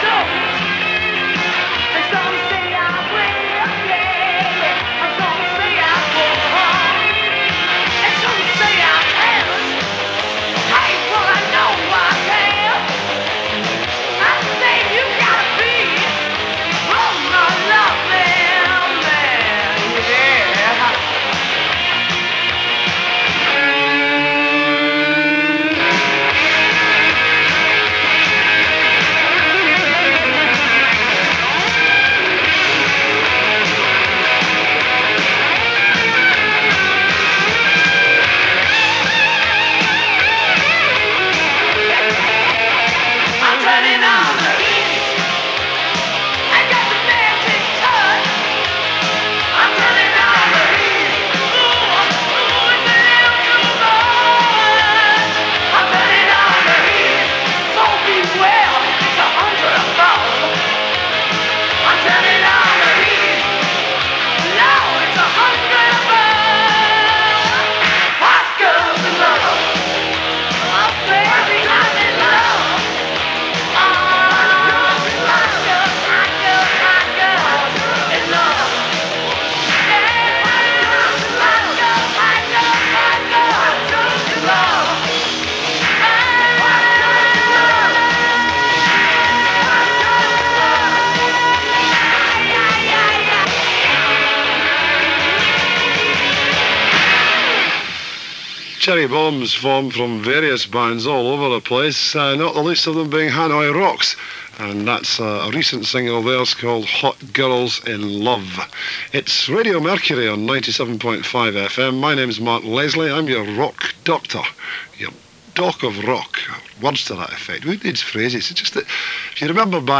As received on 97.5MHz. The audio was a a little up and down, with some distortion and transmitter issues in places.